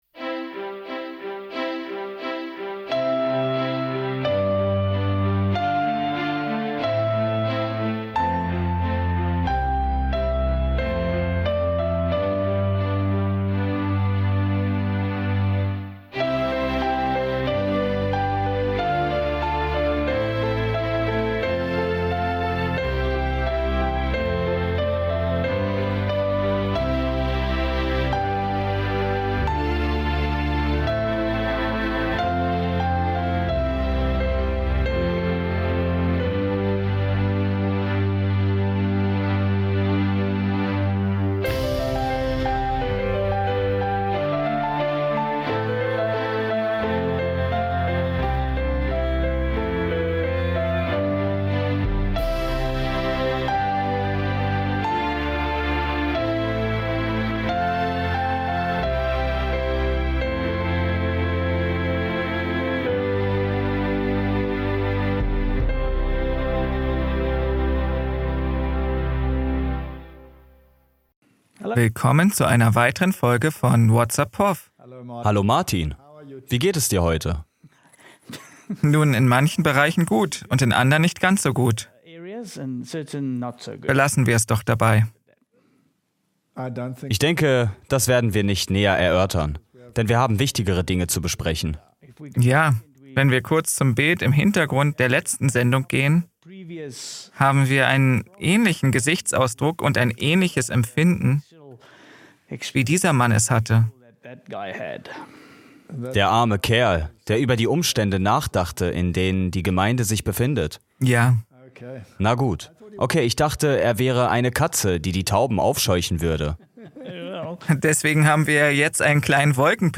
Whats Up, Prof? (Voice Over)